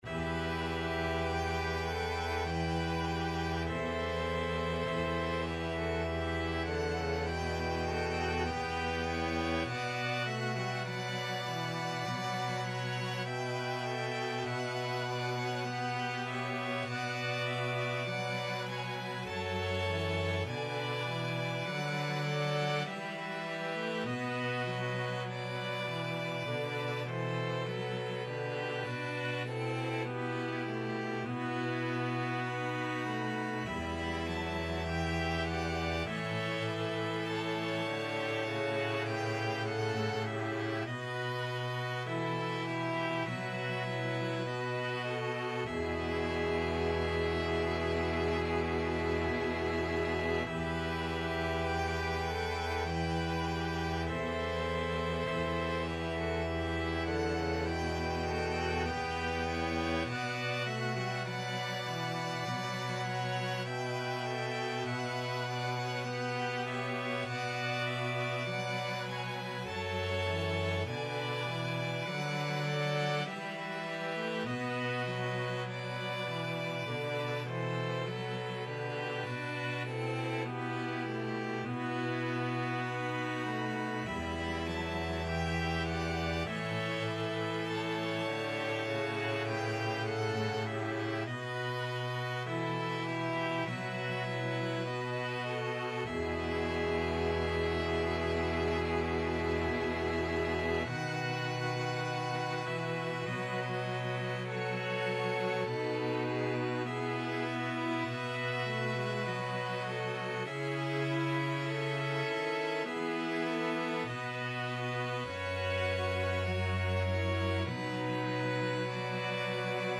Free Sheet music for String Ensemble
2/2 (View more 2/2 Music)
D major (Sounding Pitch) (View more D major Music for String Ensemble )
Classical (View more Classical String Ensemble Music)